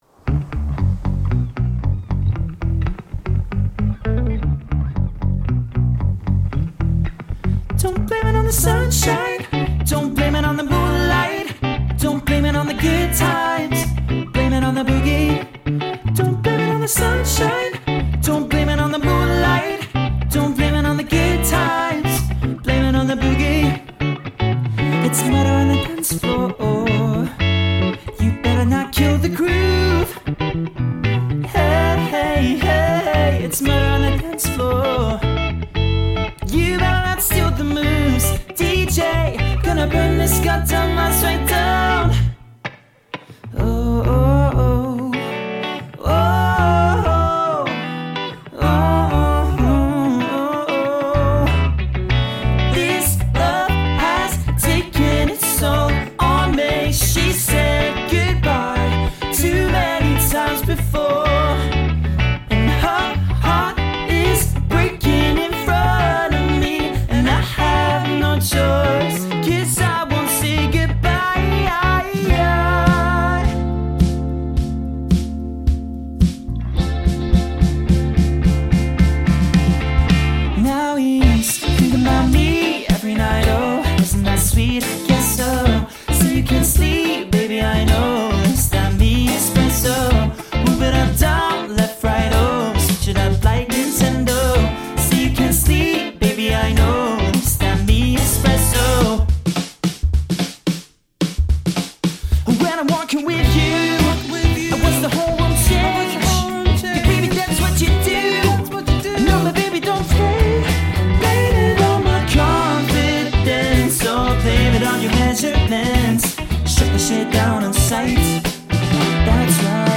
• Mixture of male and female vocals
• Three-part harmonies (for trio and full band)
Male Vocals/Guitar, Female Vocals/Bass
Male Vocals/Guitar, Female Vocals/Bass, Lead Guitar, Drums